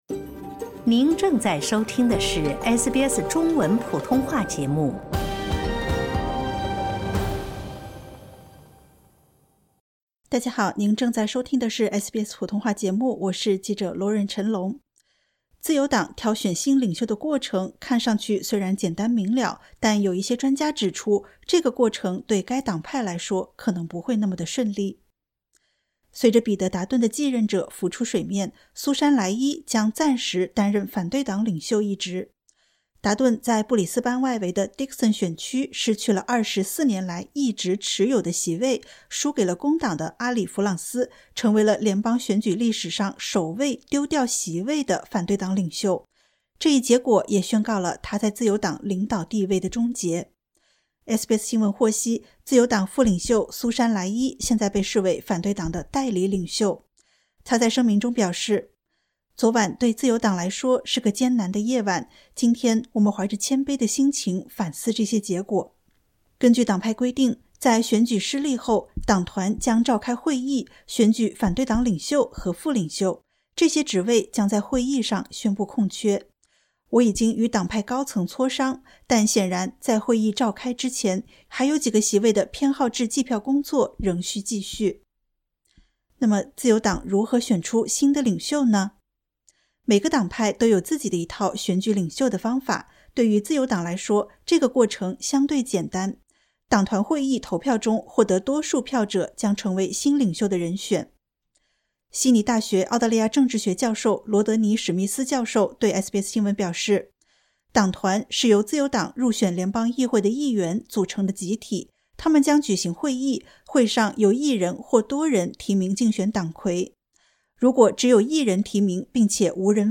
自由党挑选新领袖的过程看似简单明了，但一些专家指出，这个过程对该党派来说可能不会那么顺利。点击 ▶ 收听完整报道。